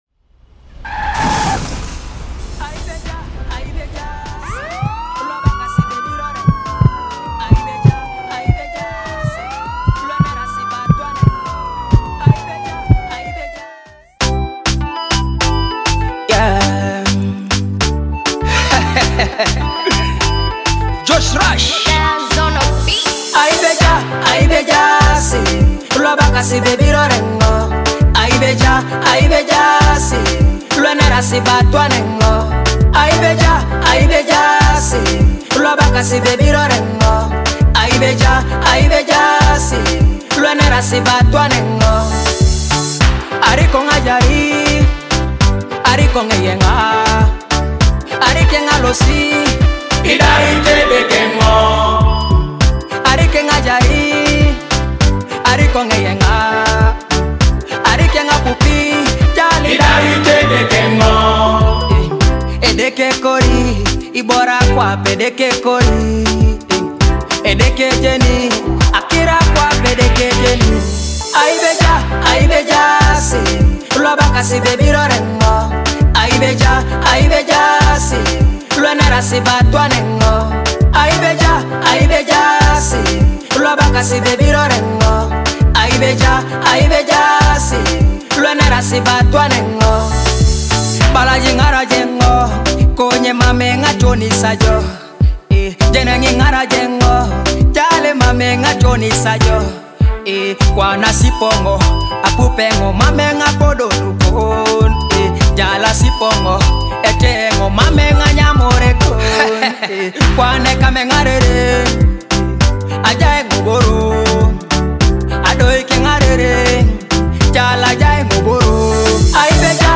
Teso music dancehall track